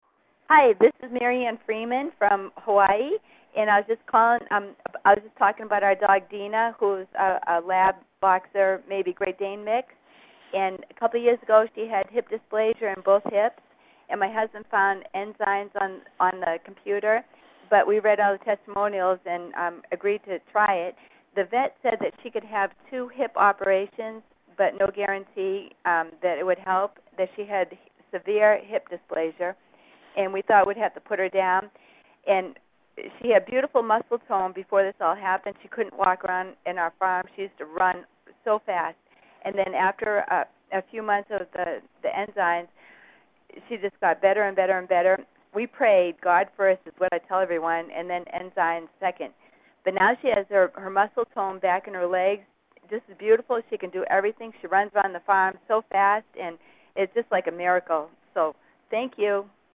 This page hosts a number of audio recordings of customer-provided verbal reviews on dog mobility troubles preventing their dogs from enjoying the life they deserve.